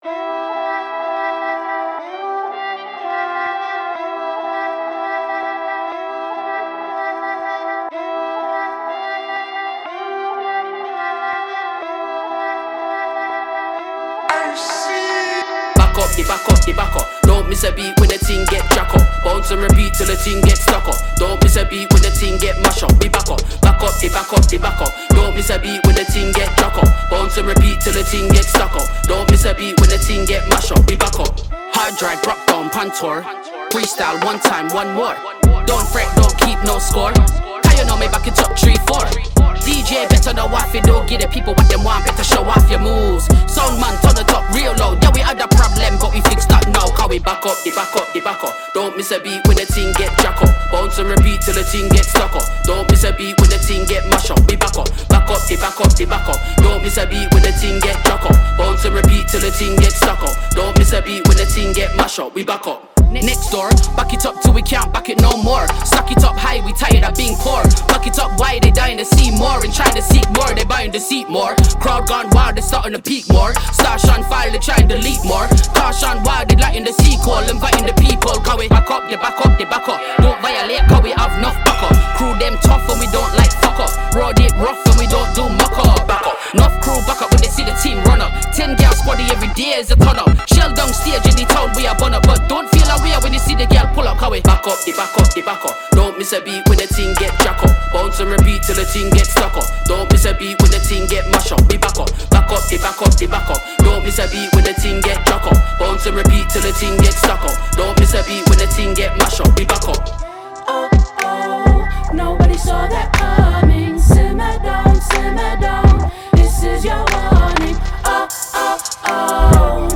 Reggae
South Pacific reggae, drum & bass and Hip-Hop flavours